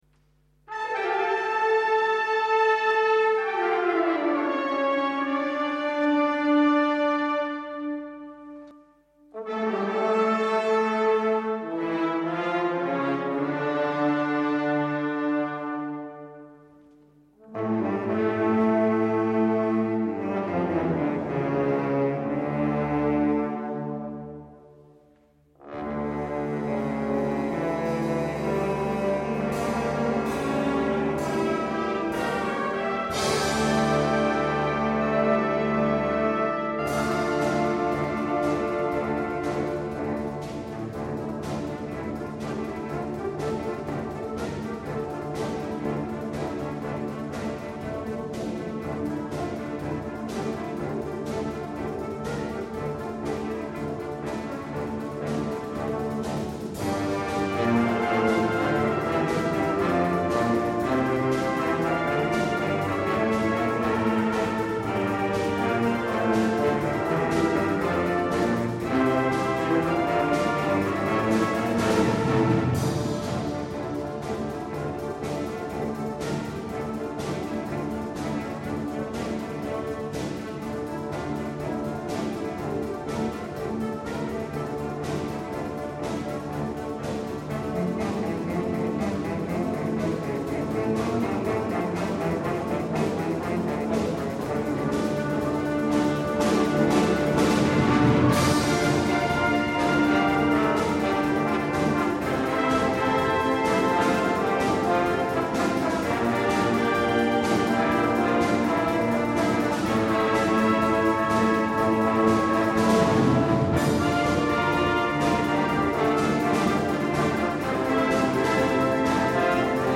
Konzert 2007